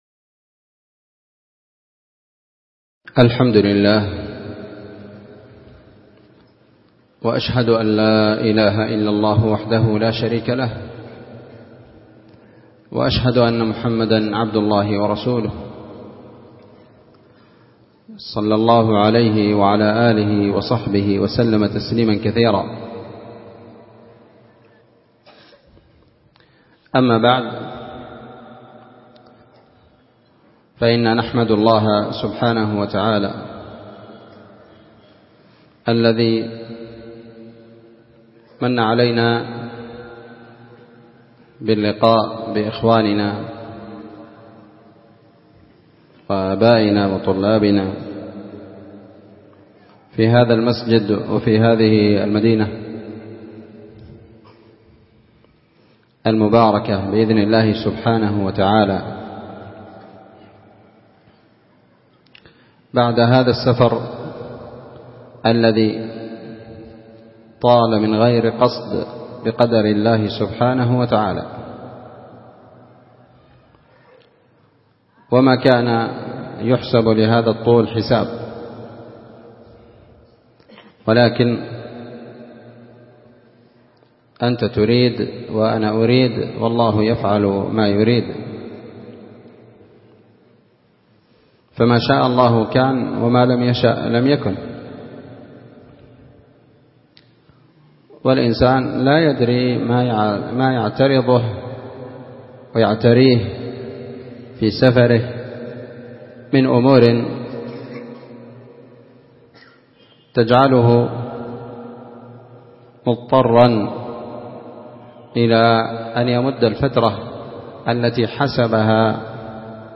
محاضرة
مسجد المجاهد- النسيرية- تعز